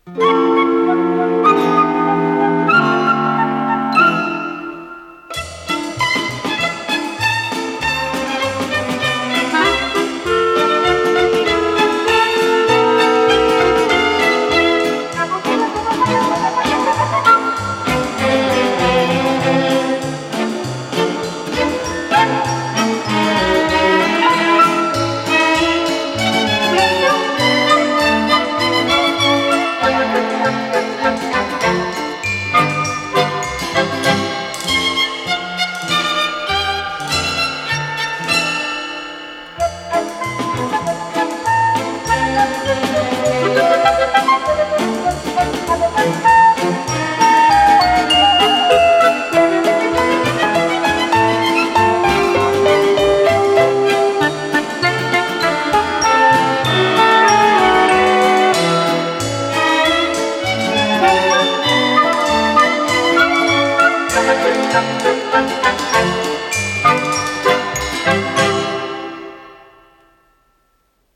с профессиональной магнитной ленты
ПодзаголовокЗаставка, ля минор
ВариантДубль моно